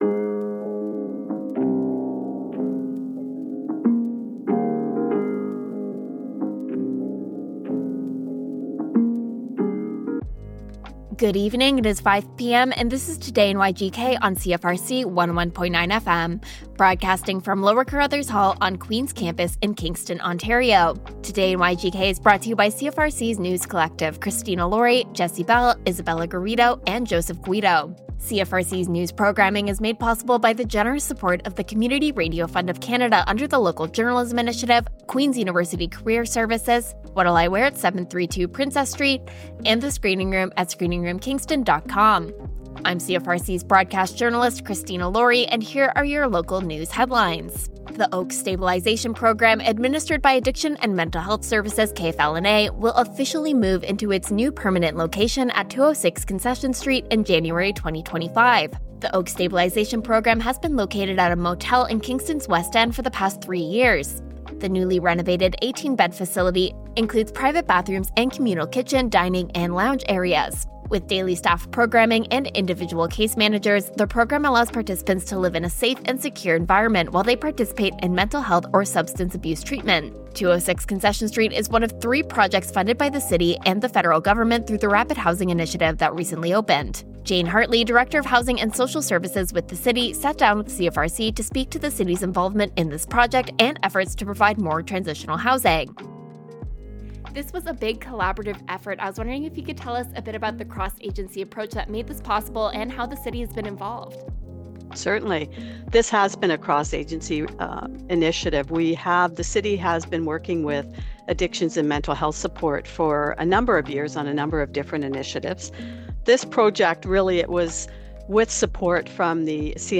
Local news with CFRC’s News Team.